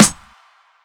f_snr.wav